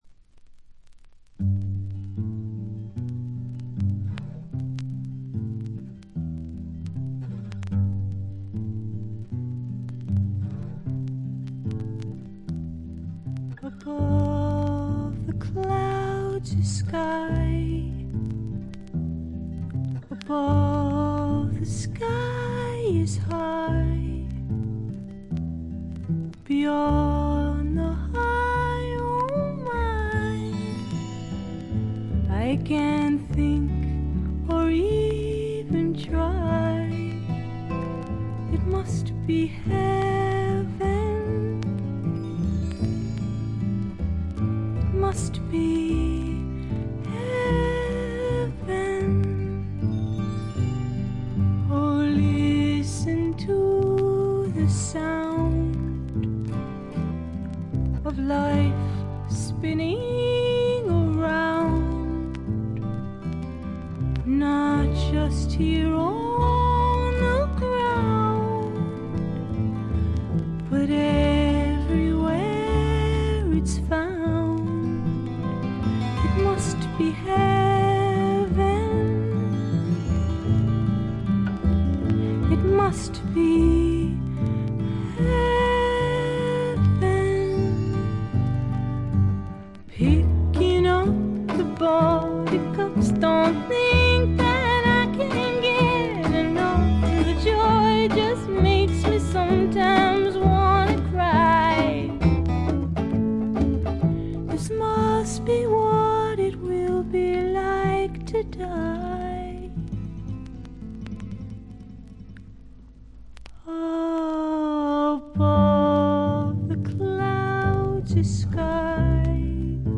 プレスがいまいちなのか、見た目よりバックグラウンドノイズやチリプチは多め大きめ。凶悪なものや周回ノイズはありません。
それを支えるシンプルなバックも見事！の一言。
試聴曲は現品からの取り込み音源です。